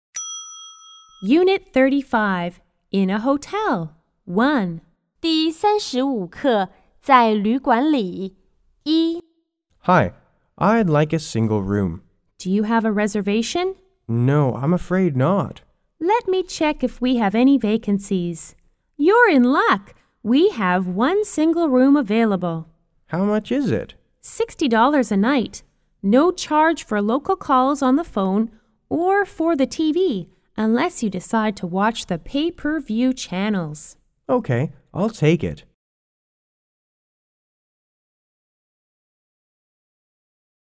T= Traveler R= Receptionist